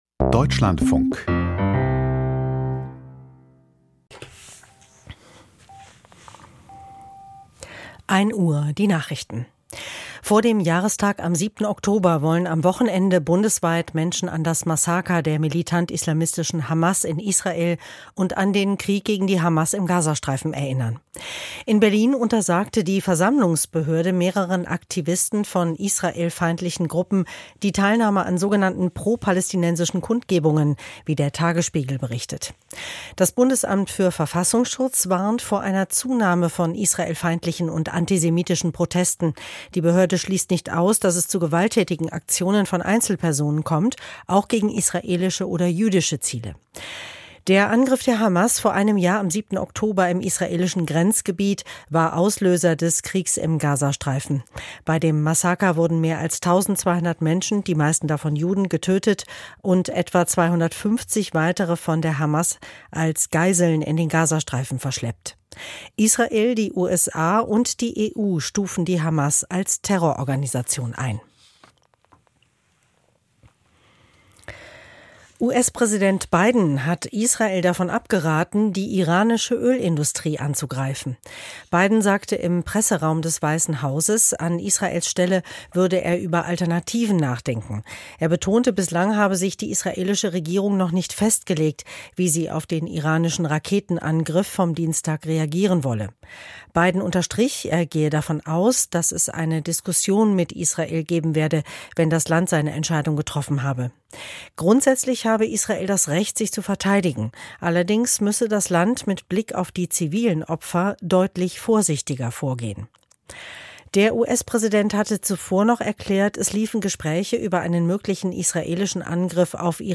Die Deutschlandfunk-Nachrichten vom 05.10.2024, 00:59 Uhr